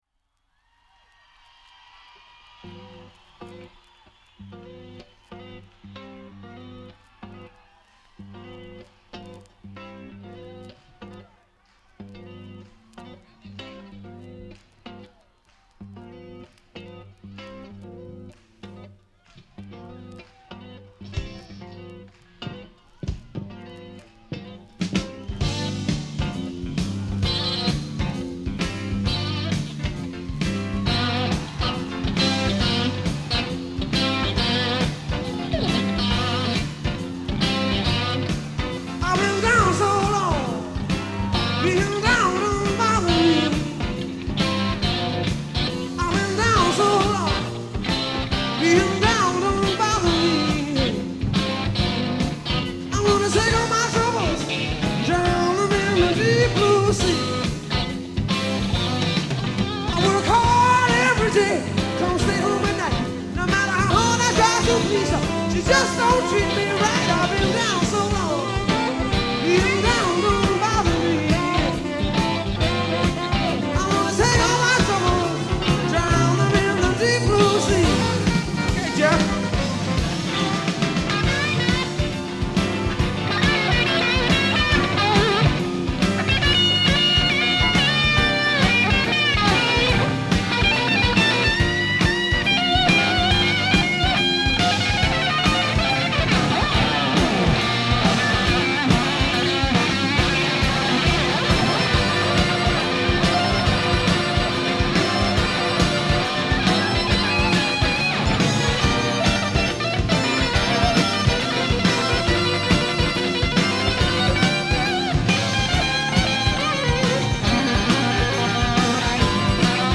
guitar
Vinyl rip